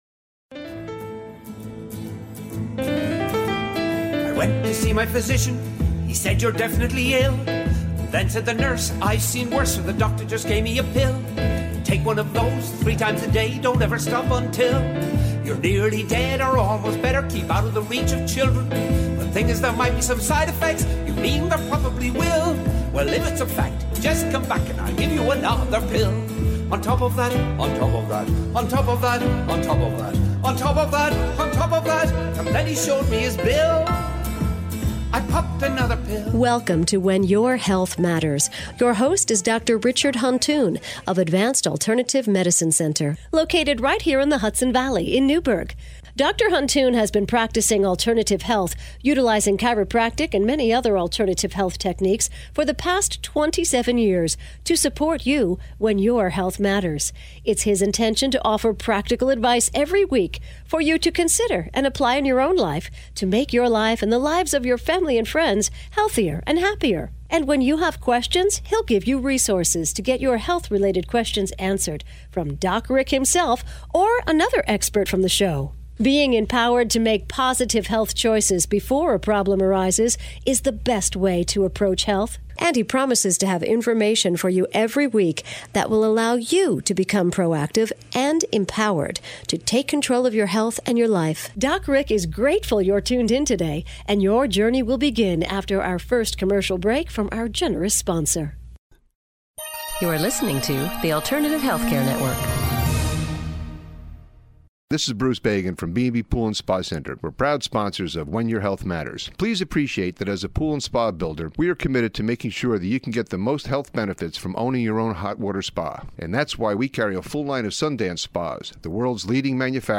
Part 2 of a conversation on how hands on healing work follows God's natural laws and is consistent with Christian teachings